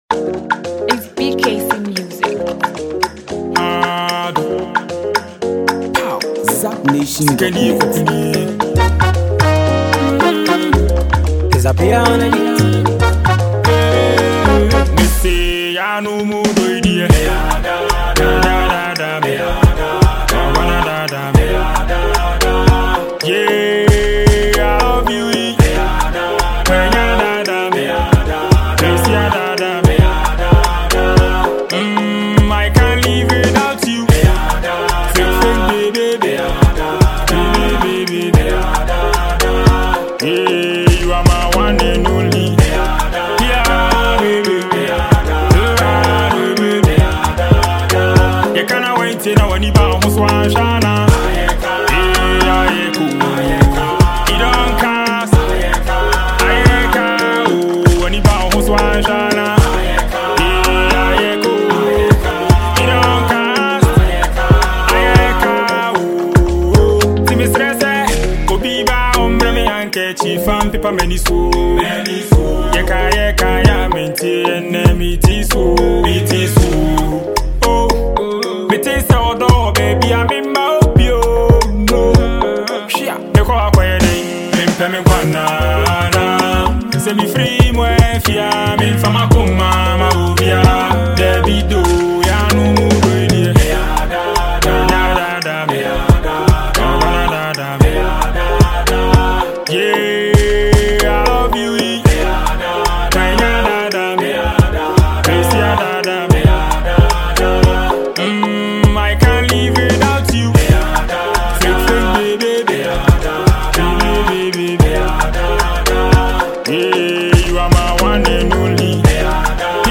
Known for his unique blend of hip-hop and highlife music
With infectious beats, catchy lyrics, and a smooth flow